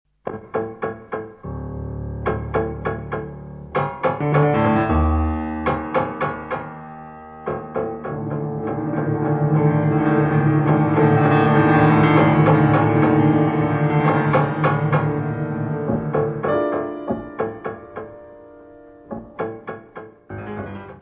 : stereo; 12 cm + folheto
Music Category/Genre:  New Musical Tendencies